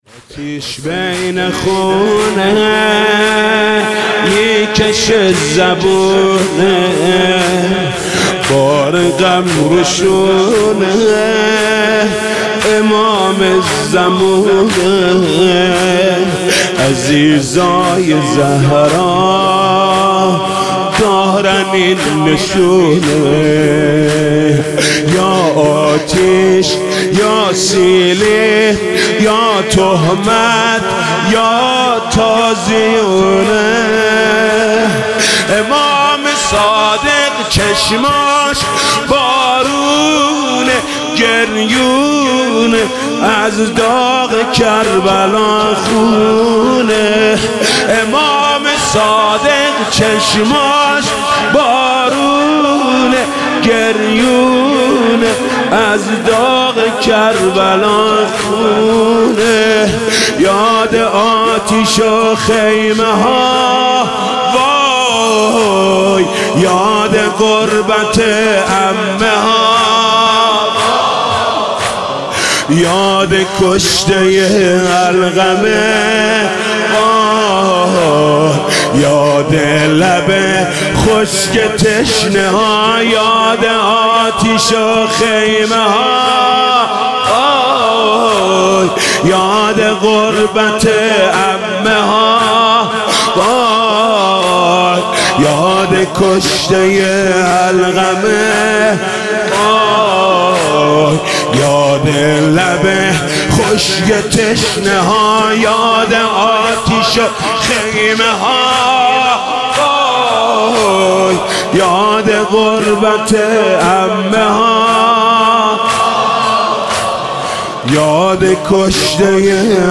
«شهادت امام صادق 1396» زمینه: آتیش بین خونه میکشه زبونه